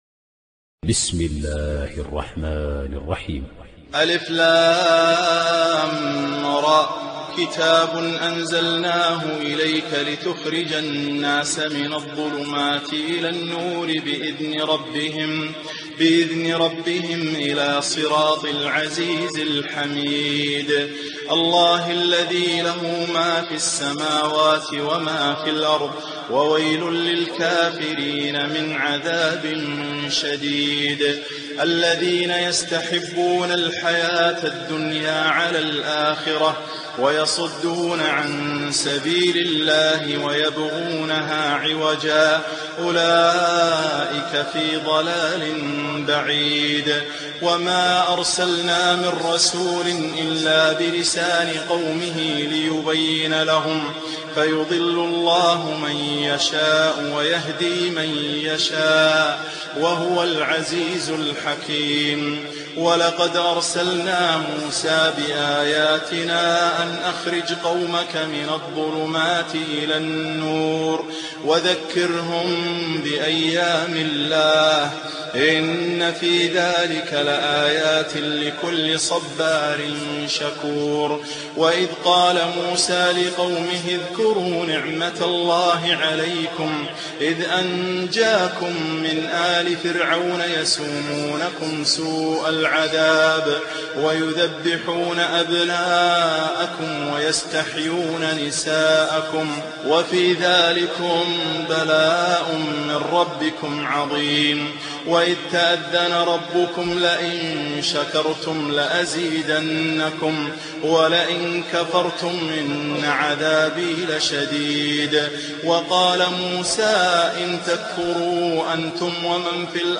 تلاوة من سورة إبراهيم